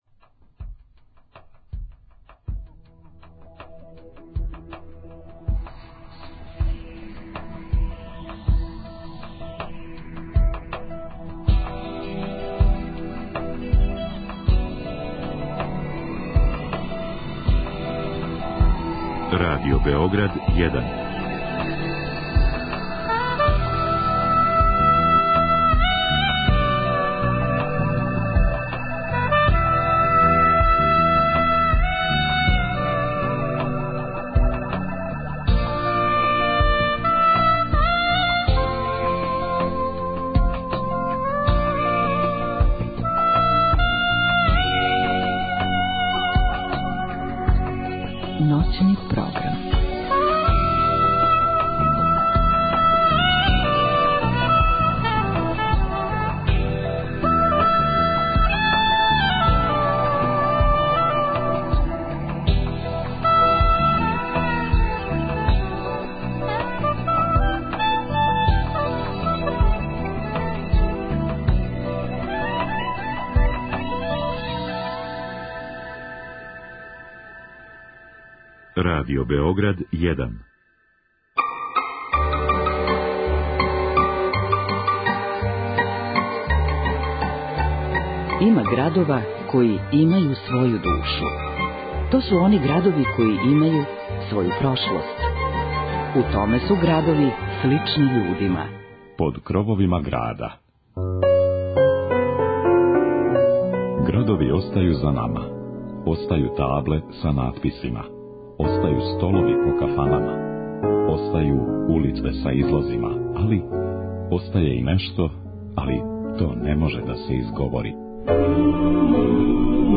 О Чубури, оној које више нема, као и људима који су ту живели и обележили једно време, уз њихове приче проткане одабраном музиком, можете чути ако останете уз таласе Радио Београда 1 ове суботе од поноћи.
преузми : 28.09 MB Ноћни програм Autor: Група аутора Сваке ноћи, од поноћи до четири ујутру, са слушаоцима ће бити водитељи и гости у студију, а из ноћи у ноћ разликоваће се и концепт програма, тако да ће слушаоци моћи да изаберу ноћ која највише одговара њиховом укусу, било да желе да слушају оперу или их интересује технологија.